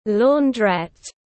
Launderette /ˌlɔːnˈdret/